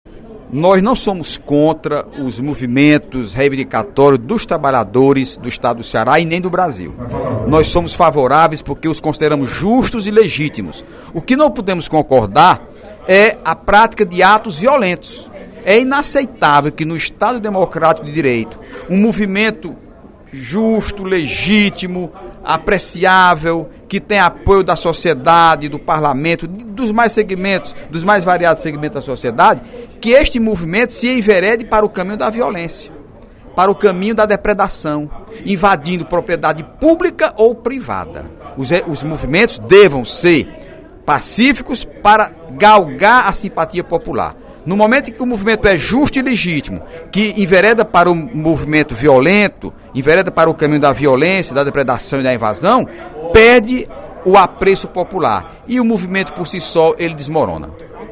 O deputado Heitor Férrer (PDT) repudiou, na manhã desta quarta-feira (30/05), durante a sessão plenária, a ação de trabalhadores que resultou na depredação de instalações do Jornal Diário do Nordeste.